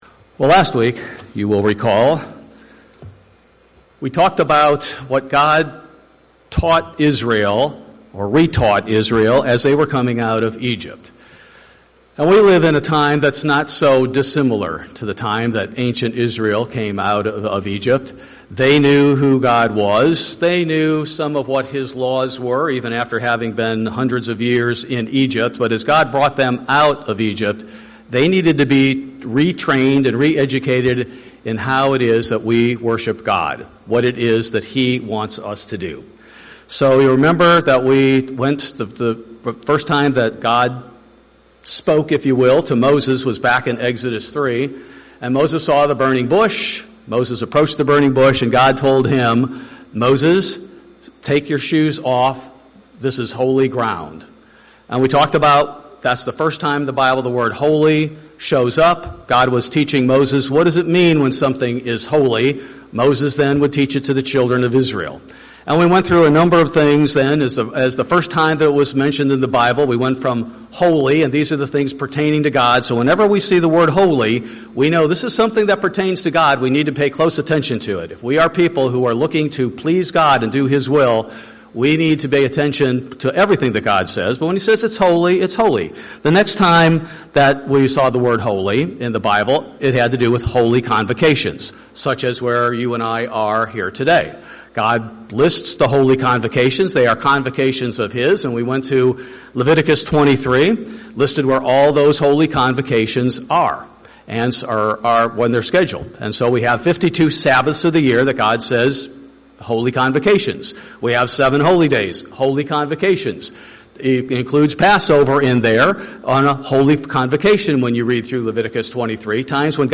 In this continuation of the prior sermon "Holy Ground", we look at God's further instruction and focus on the observance of His Sabbath days, and discuss how the Sabbath can become the "delight" to us that God wants it to be.